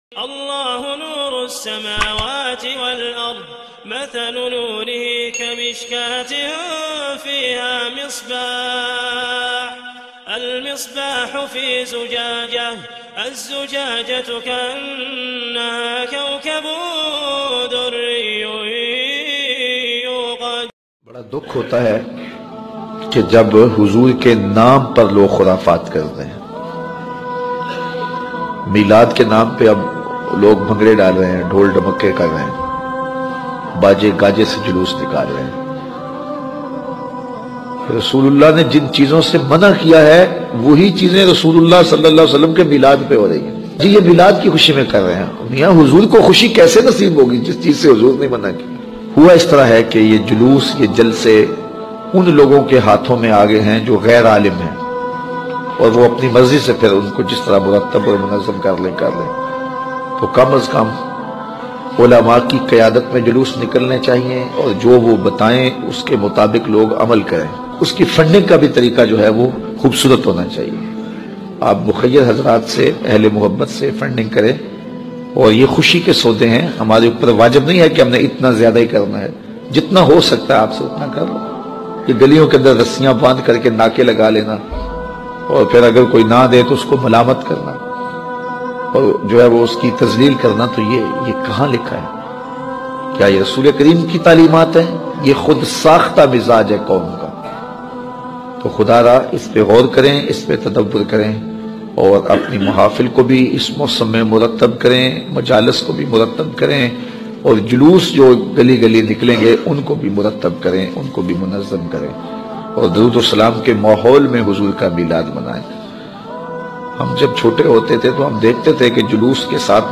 bayan about Milad Shareef
Bayan-About-Milad.mp3